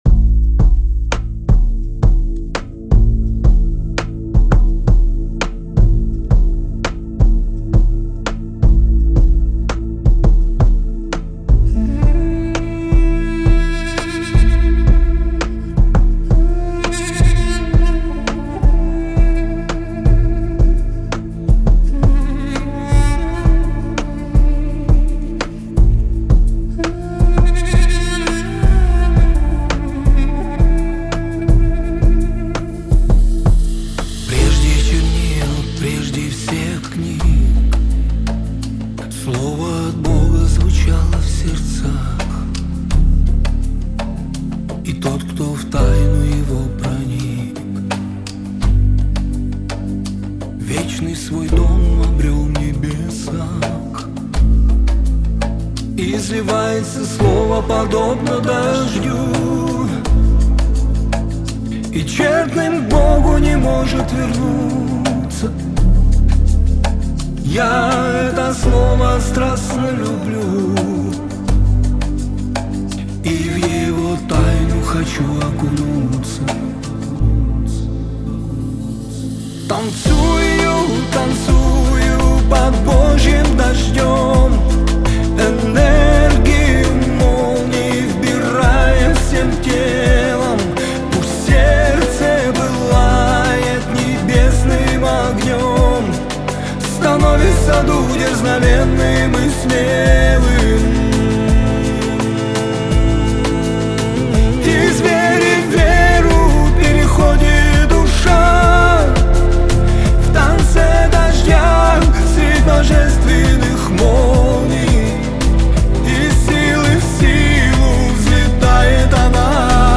175 просмотров 194 прослушивания 26 скачиваний BPM: 85